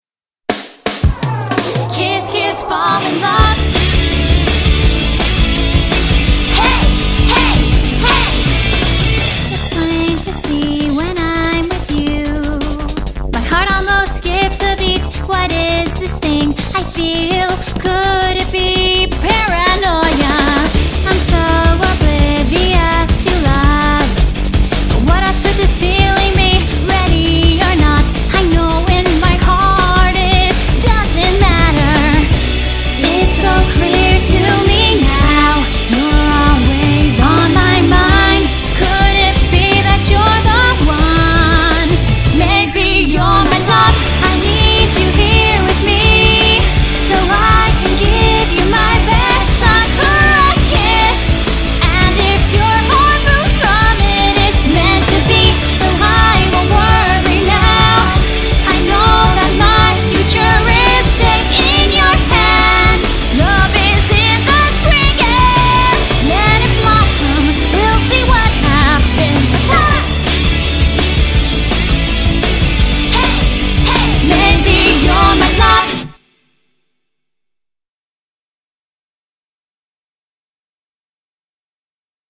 BPM83-166
Audio QualityCut From Video